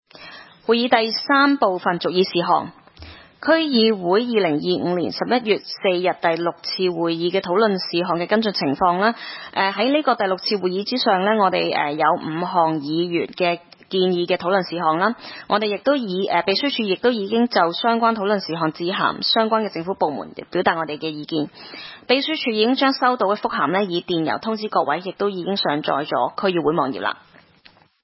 區議會大會的錄音記錄
西貢區議會第一次會議
西貢將軍澳政府綜合大樓三樓